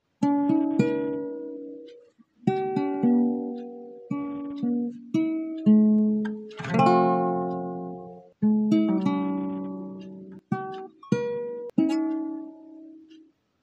For an example, play the left wing, the head (I), hit the body (rest), then the right wing (reverse.)